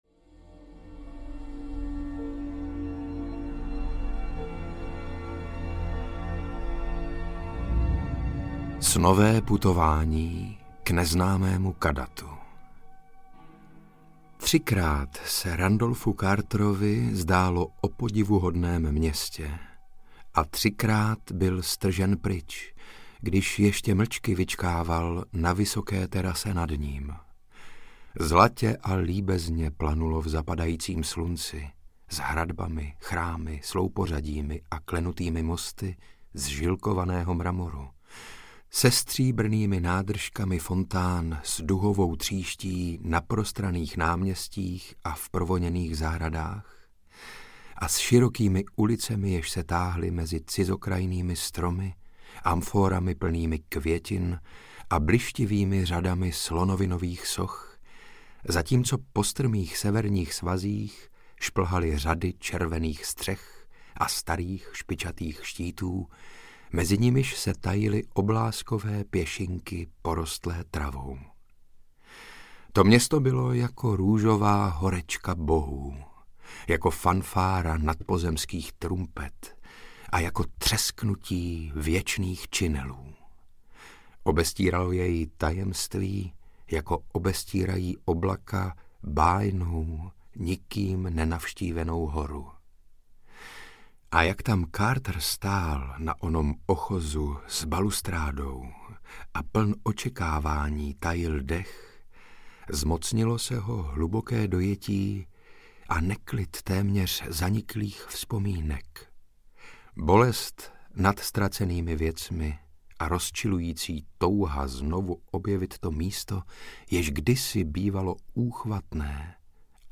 Snové putování k neznámému Kadathu audiokniha
Ukázka z knihy
• InterpretSaša Rašilov ml.